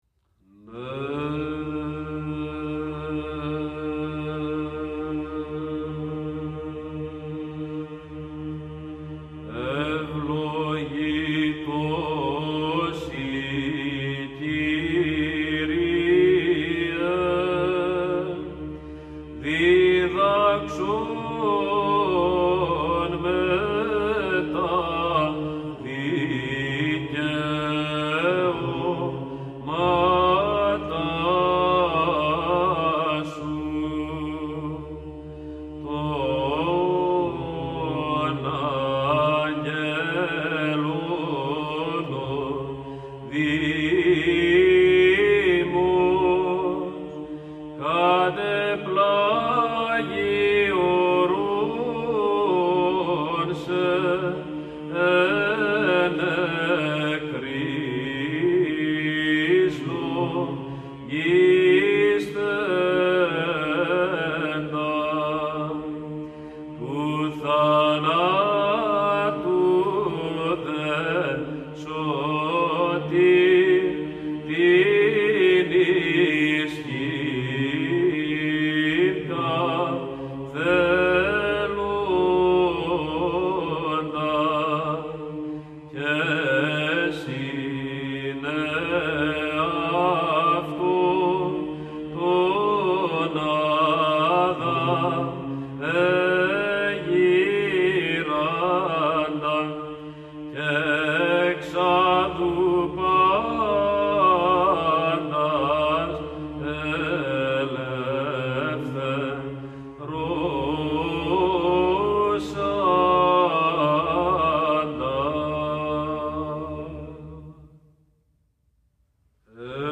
Muzica psaltica